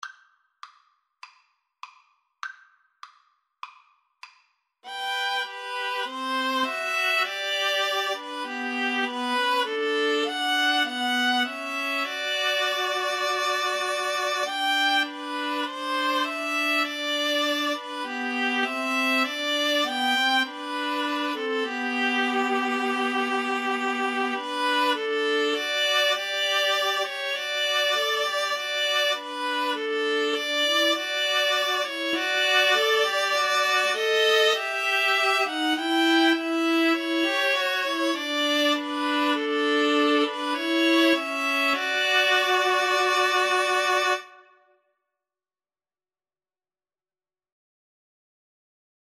Free Sheet music for Viola Trio
G major (Sounding Pitch) (View more G major Music for Viola Trio )
4/4 (View more 4/4 Music)
Classical (View more Classical Viola Trio Music)